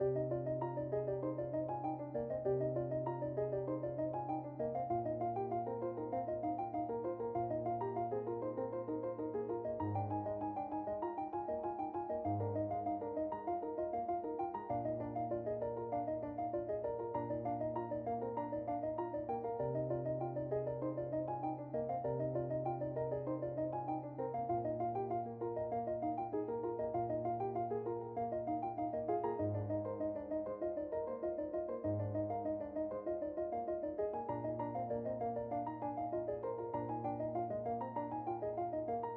哼哼1
描述：良好的背景噪音，也许适合城市环境
标签： 环境 冷静 全面
声道立体声